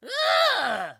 Звуки гоблина
Здесь вы найдете рычание, скрежет, зловещий смех и другие устрашающие эффекты в высоком качестве.